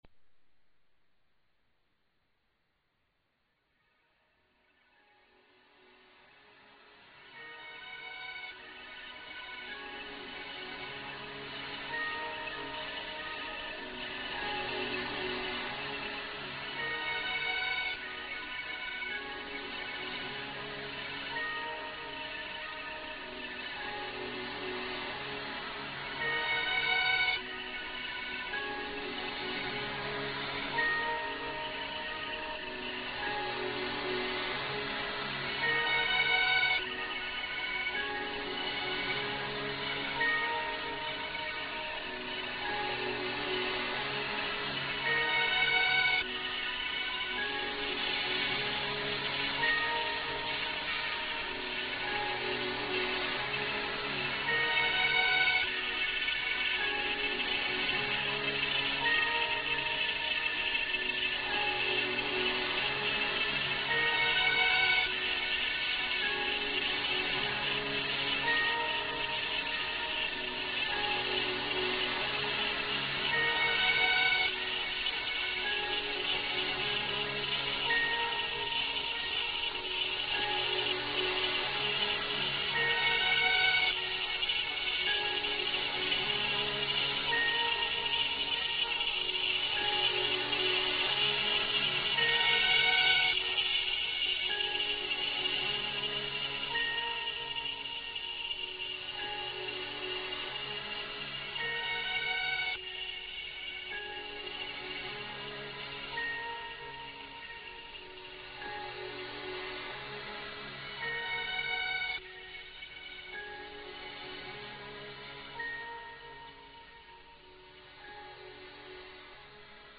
a recording of a concert now available!